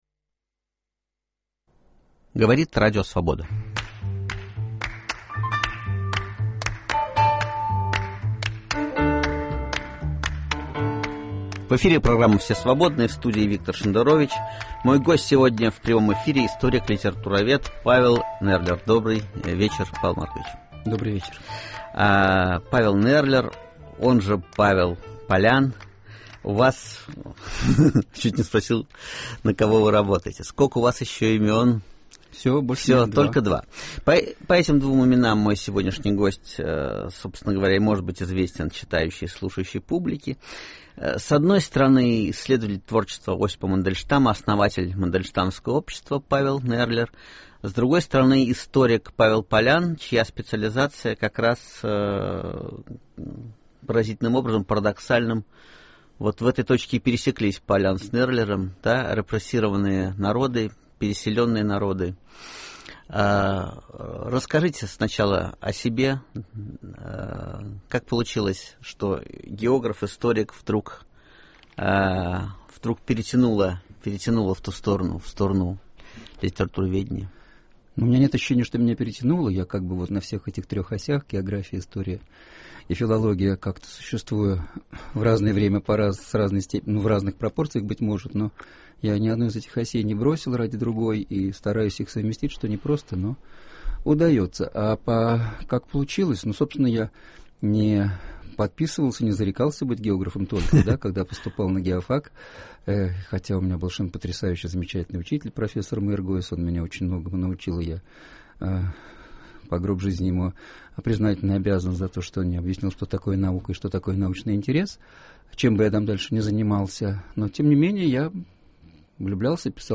В гостях у Виктора Шендеровича